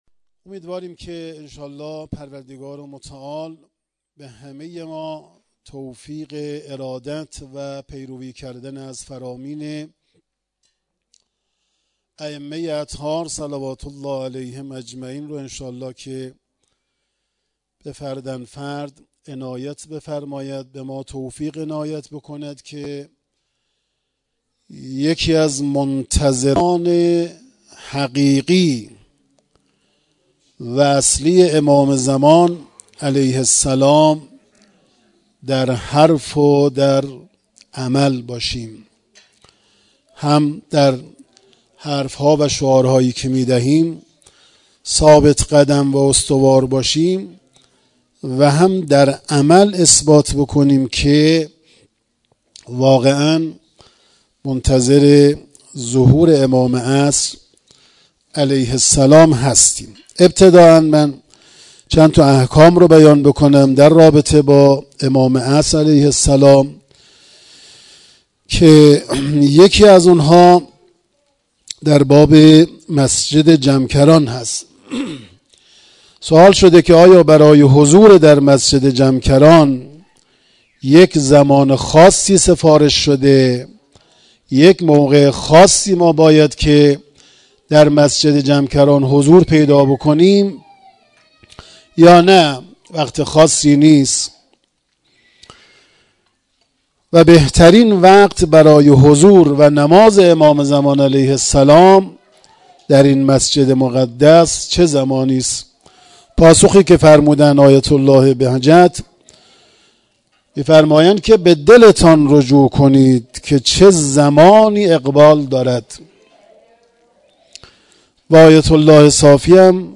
سخنرانی
مراسم جشن سالروز امامت امام زمان(عج).mp3